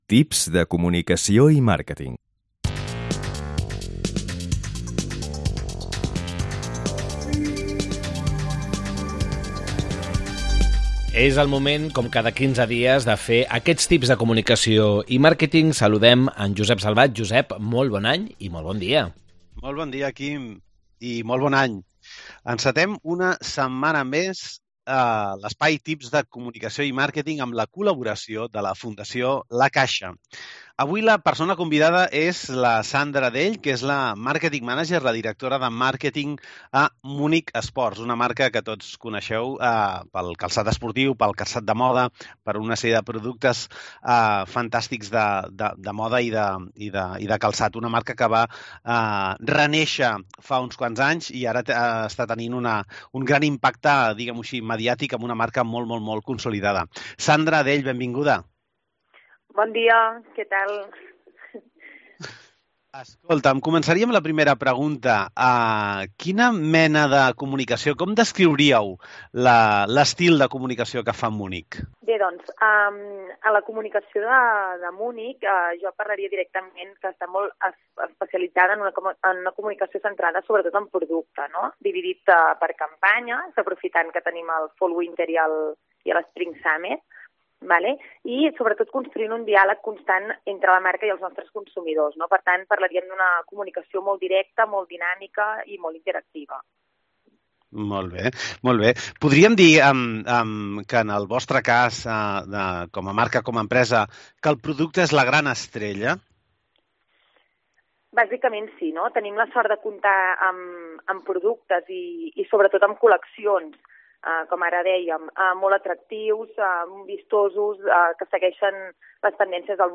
Tips Comunicació i Màrqueting Entrevista